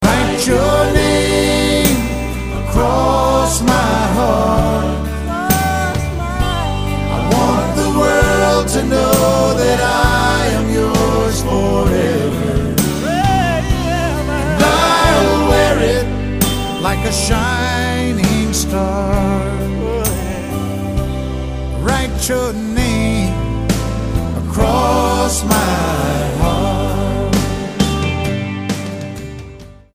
STYLE: Country
Almost entirely ballads